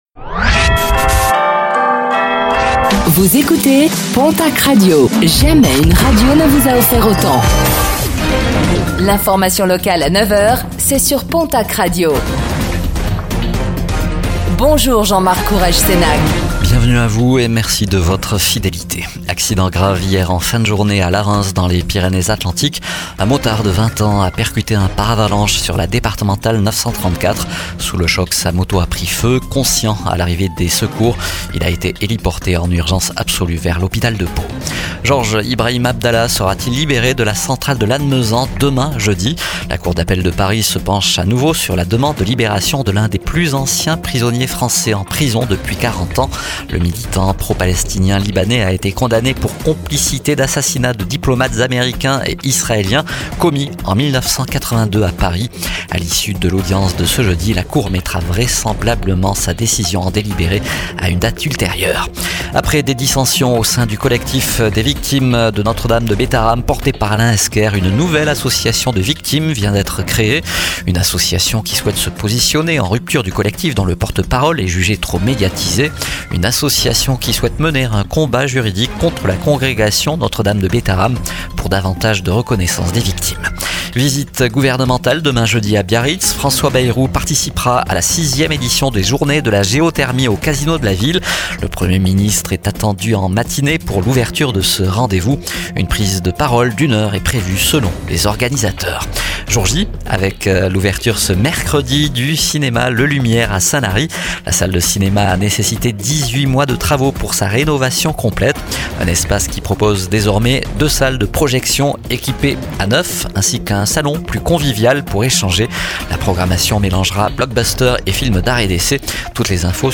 09:05 Écouter le podcast Télécharger le podcast Réécoutez le flash d'information locale de ce mercredi 18 juin 2025